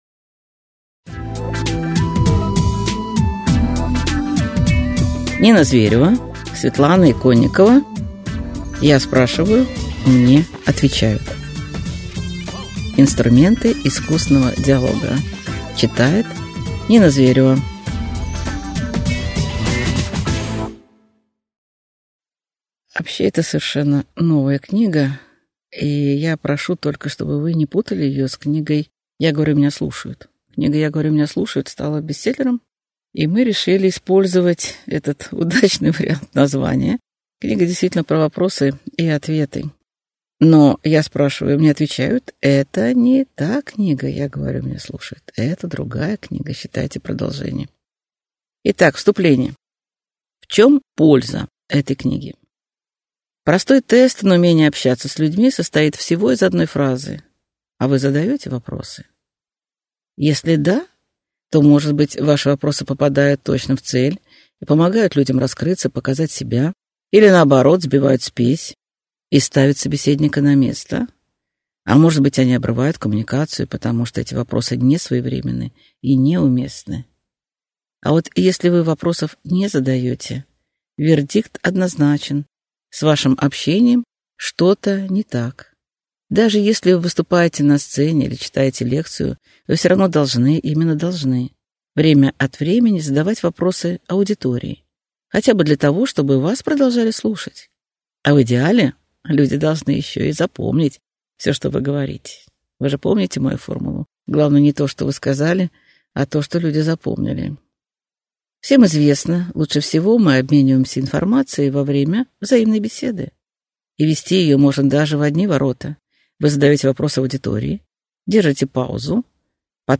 Аудиокнига Я спрашиваю – мне отвечают: Инструменты искусного диалога | Библиотека аудиокниг